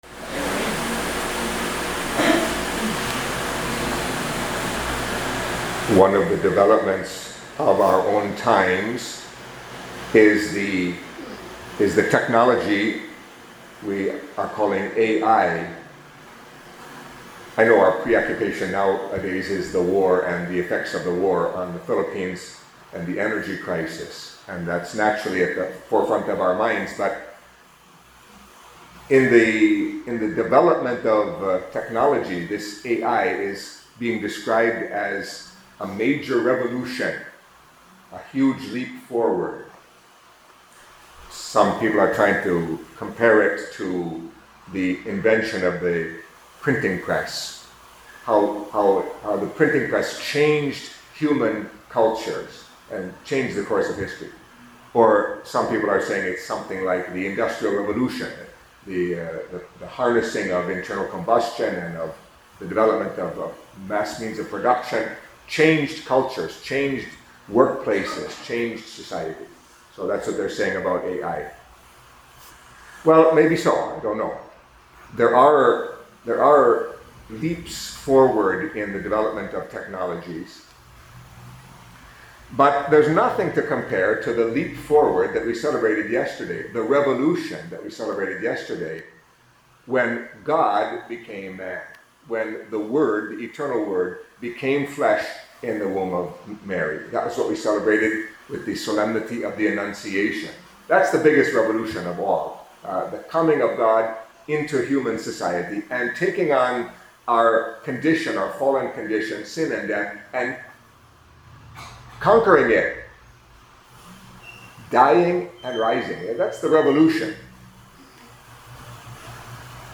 Catholic Mass homily for Thursday of the Fifth Week of Lent